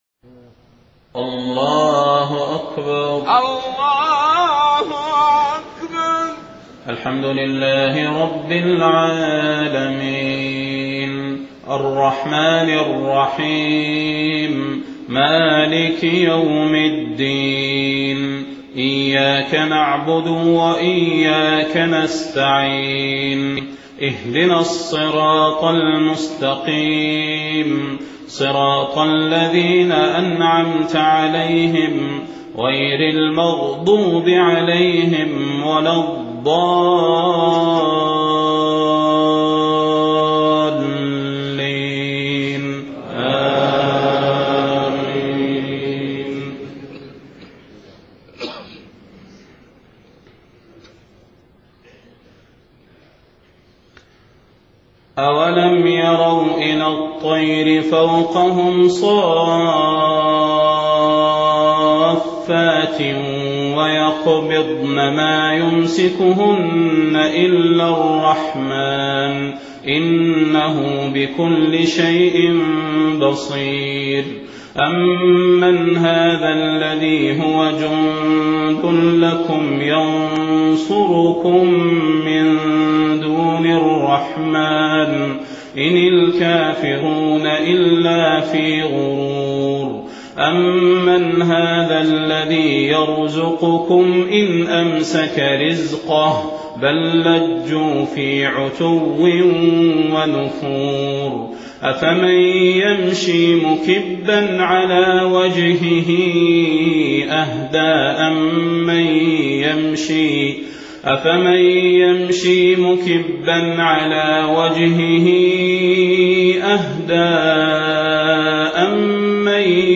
صلاة الفجر 28 محرم 1430هـ سورة الملك 19-30 الركعة الثانية فقط > 1430 🕌 > الفروض - تلاوات الحرمين